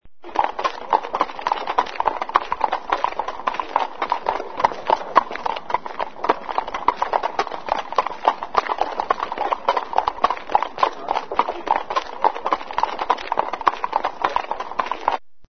caballo.mp3